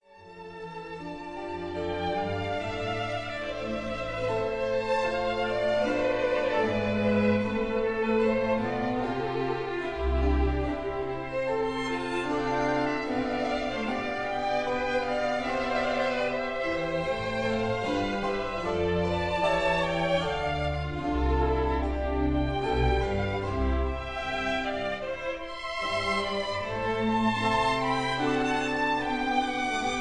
director and harpsichord*